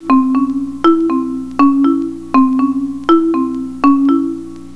In the next example we put a duration order of two elements against a melody line of three elements.